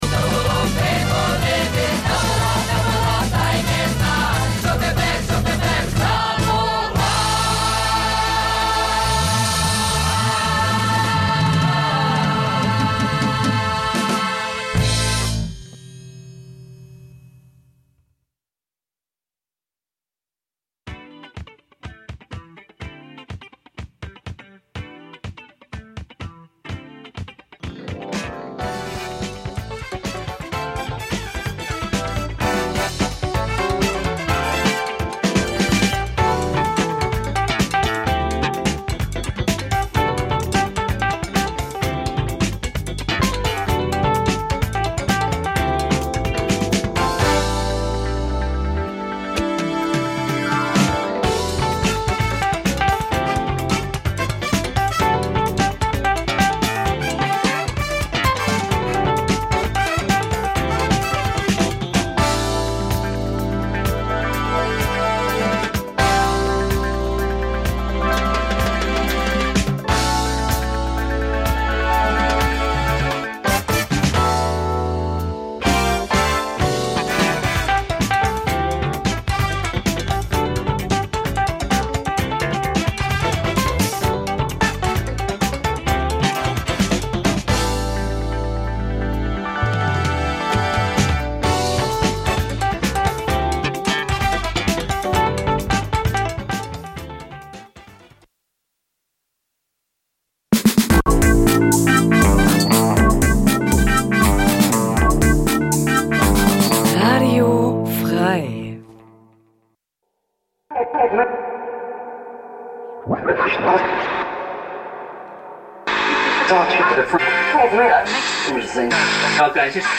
Wenn der Groove Deine Seele streichelt, die Drums ungezogen ins Ohr fl�stern, w�hrend Loops und Schleifen in entspannten Kreisen schweifen und dabei mehr gelacht als gedacht wird, sind Deine Ohren bei Radio Bounce - Gurgelnd knusprige Wellen aus der Hammerschmiede f�r leidenschafltiche Sch�ngeister, pudelnackt!